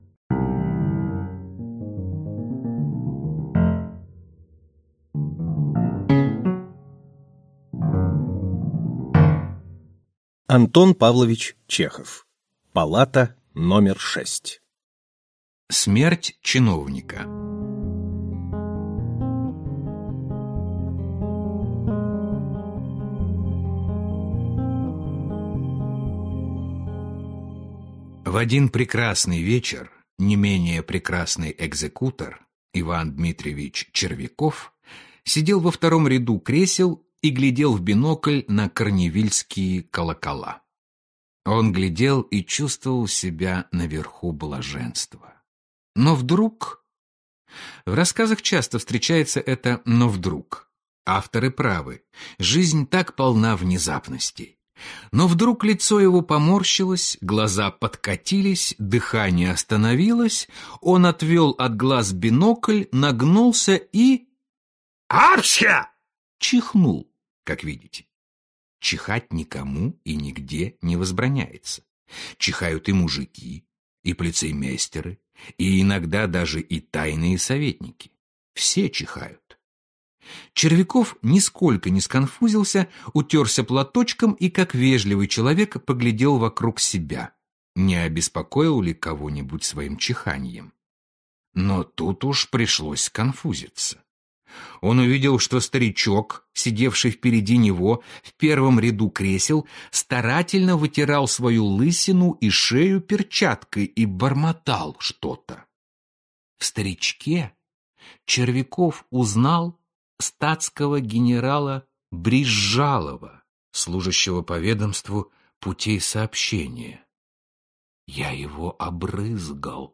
Аудиокнига Палата № 6 | Библиотека аудиокниг
Прослушать и бесплатно скачать фрагмент аудиокниги